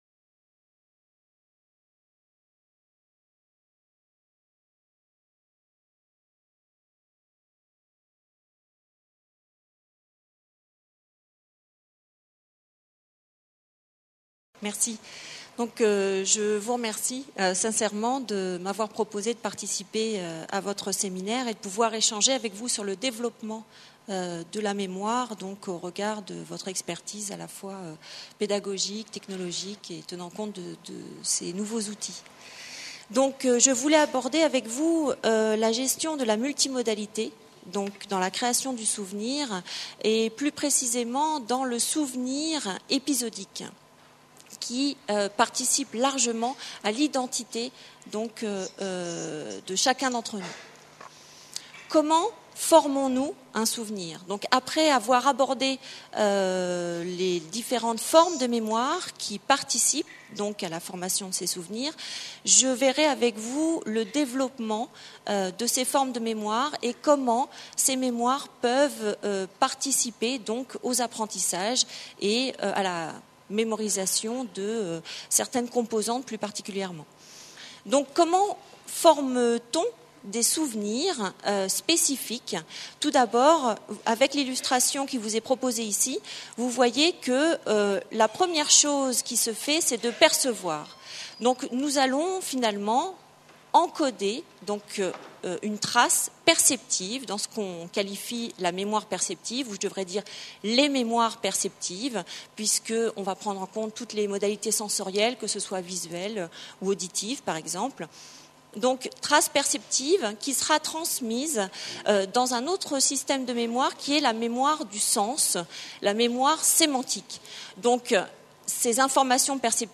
PREMIER SÉMINAIRE INTERNATIONAL SANKORÉ DE RECHERCHE UNIVERSITAIRE SUR LA PÉDAGOGIE NUMÉRIQUE Conférence-Débat : INSERM / SANKORE ZOOM SUR LES NEUROSCIENCES Que disent les neurosciences sur l’Education numérique ?